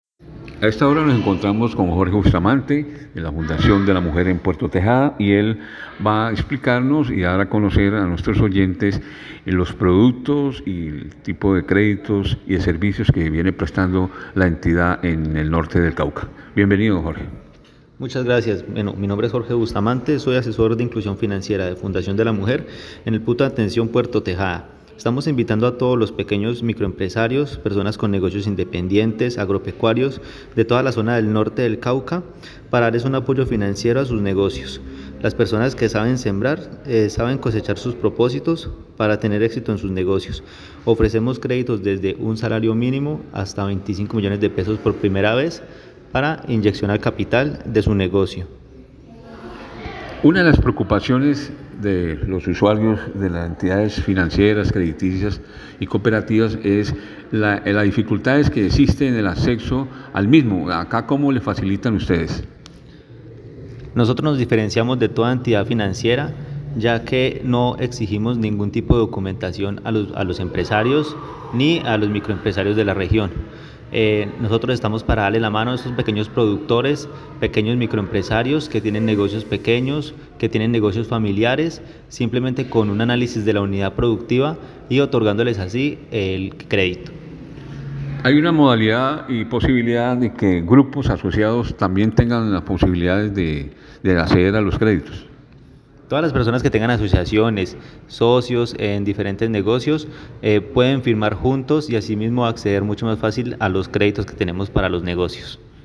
Entrevista-Asesor-Fdlm-Puerto-Tejada-La-Suprema-Fm.mp3